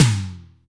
ODD TOM LO2.wav